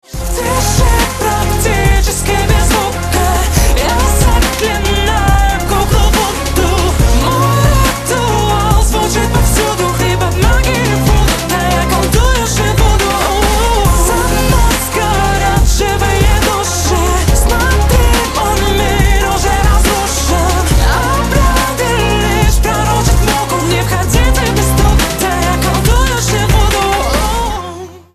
• Качество: 128, Stereo
мужской вокал